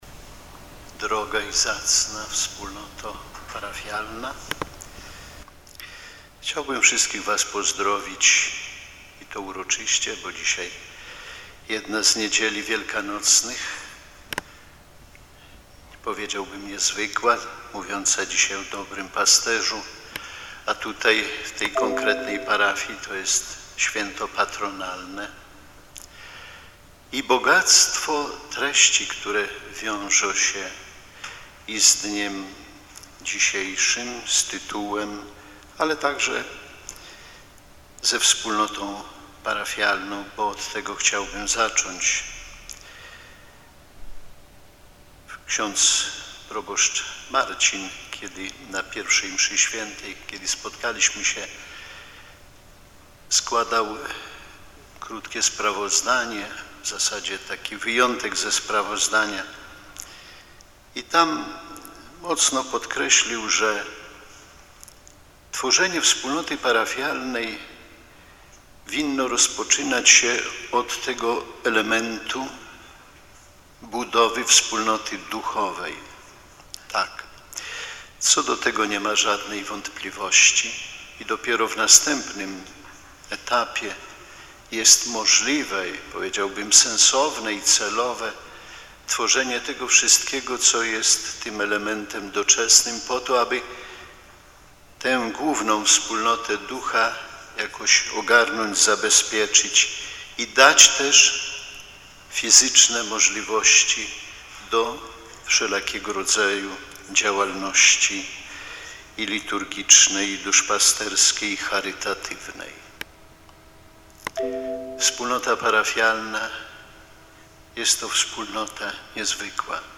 Ordynariusz diecezji warszawsko-praskiej bp Romuald Kamiński przewodniczył 3 maja uroczystej sumie odpustowej w parafii Dobrego Pasterza w Warszawie.
W homilii, duchowny przypomniał, że każdy z nas ma w życiu zadanie bycia przewodnikiem dla innych.